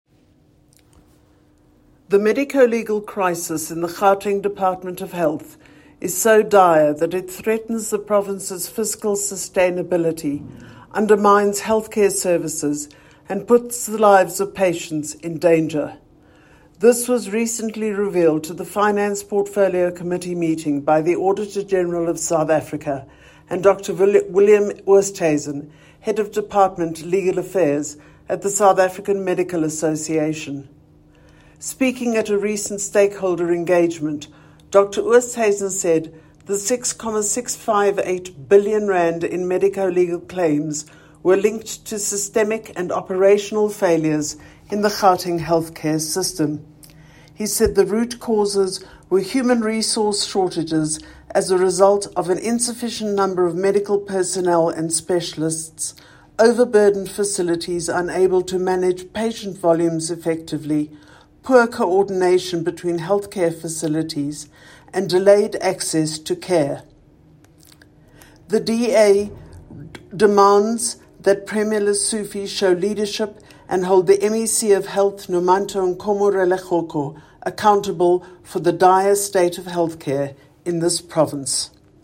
soundbite by Madeleine Hicklin MPL.